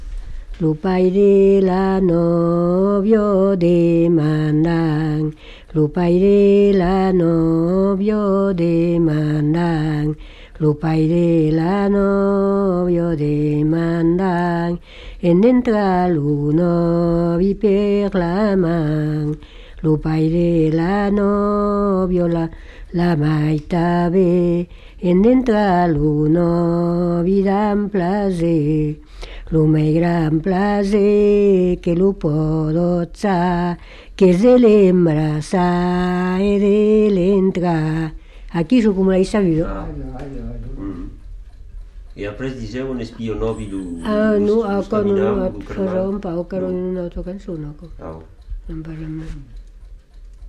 Lieu : Moncrabeau
Genre : chant
Effectif : 1
Type de voix : voix de femme
Production du son : chanté